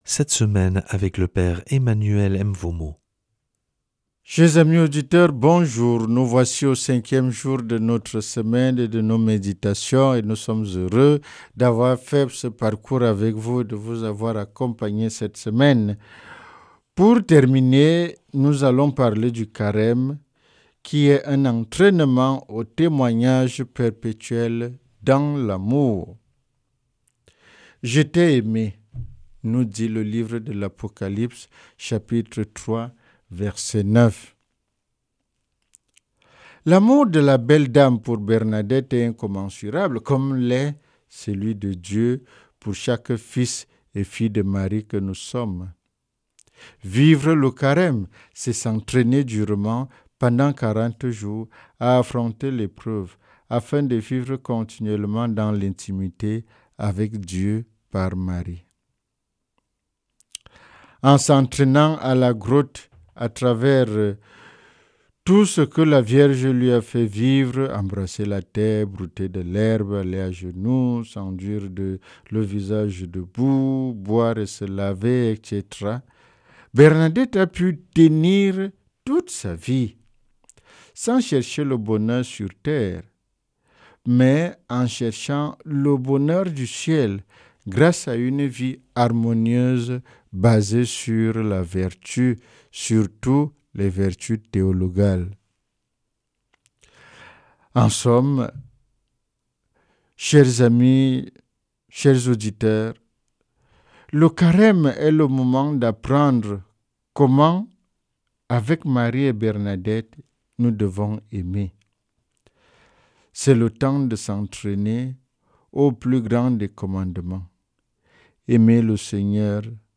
L'enseignement marial